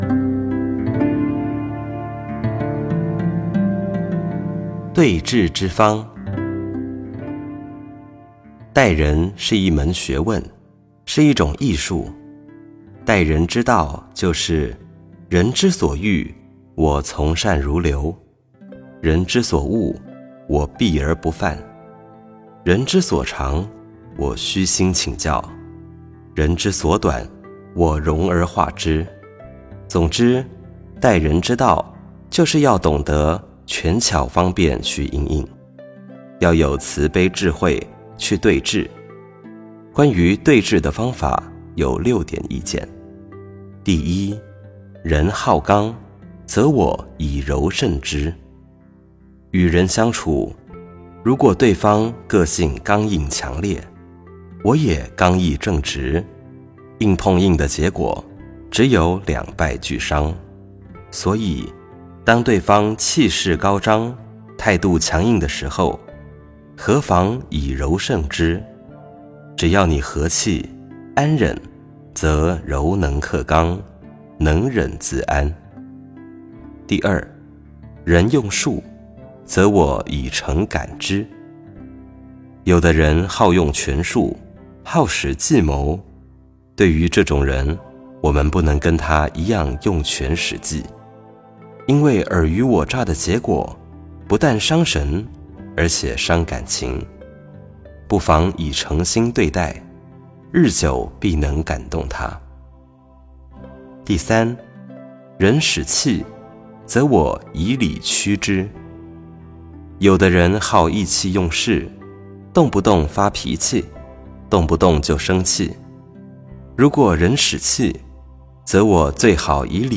佛音 冥想 佛教音乐 返回列表 上一篇： 20.